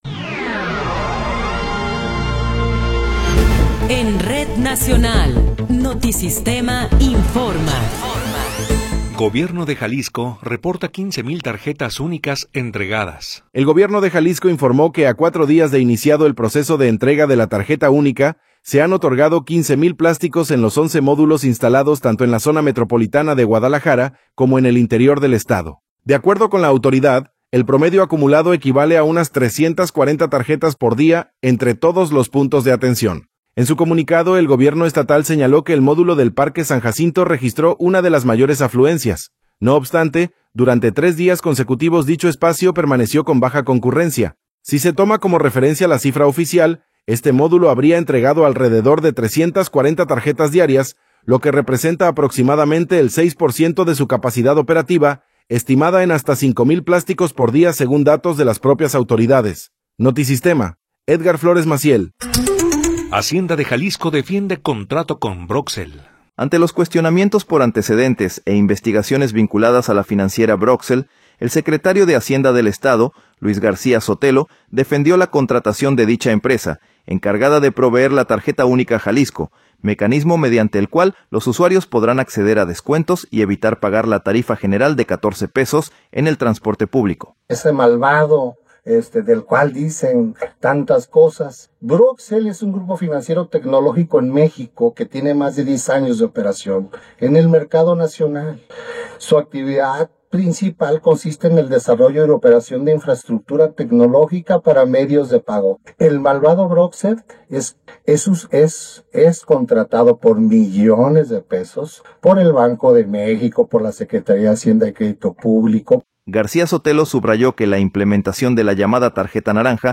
Noticiero 18 hrs. – 22 de Enero de 2026